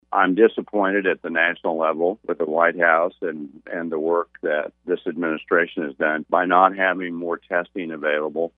Hawk also talked to KMAN about the supply of COVID-19 testing supplies in Kansas, saying he is frustrated with the federal government’s approach.